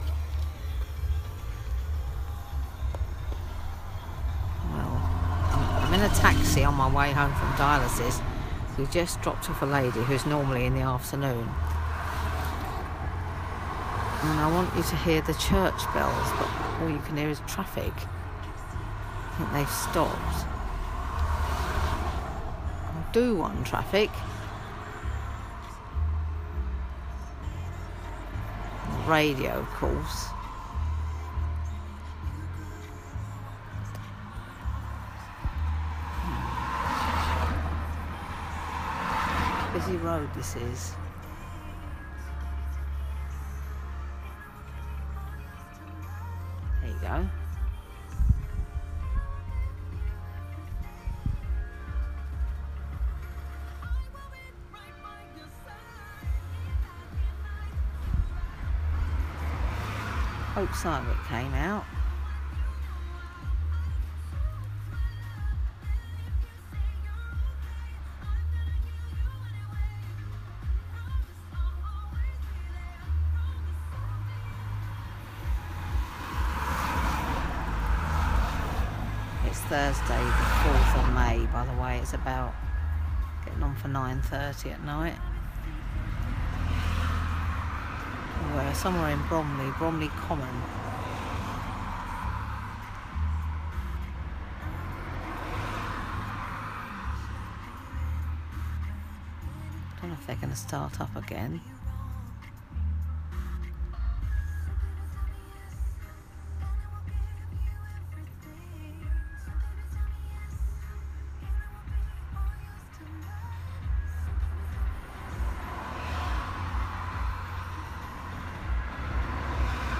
Trying to record church bells without much success, Thursday 4 May 2017